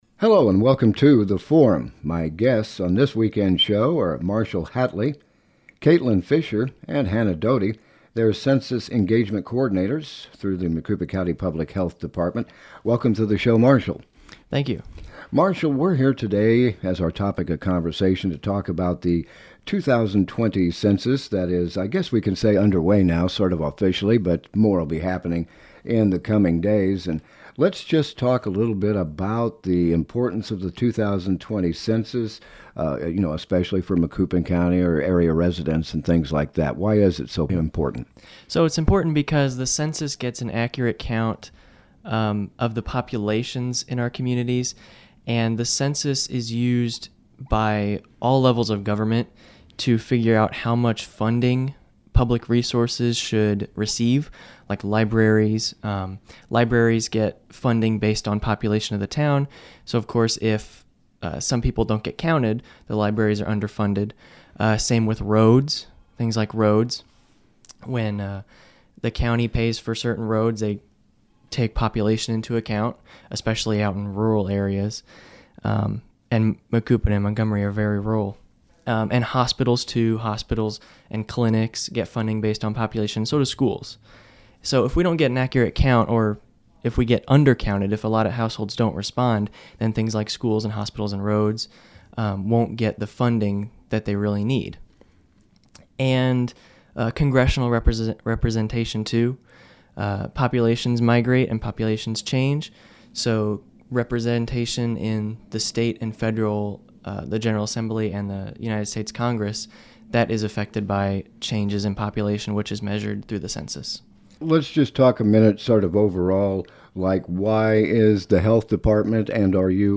Census Engagement Coordinators